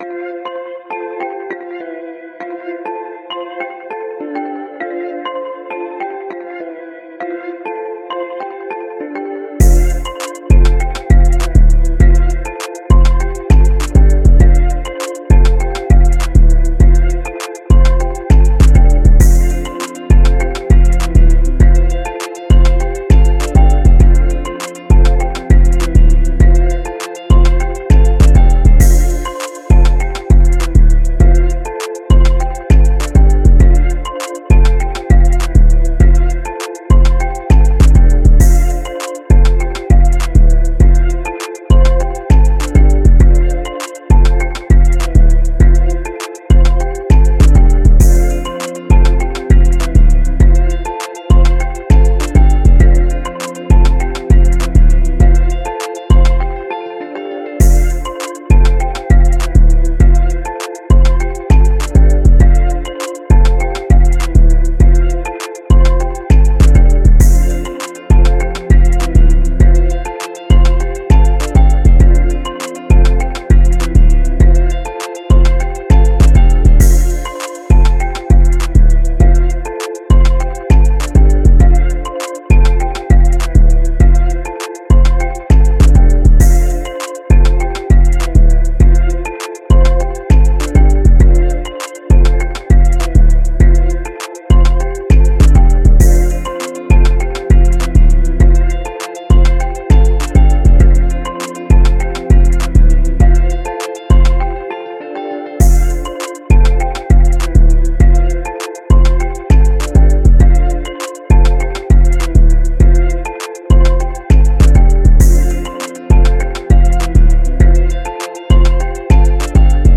Key:Am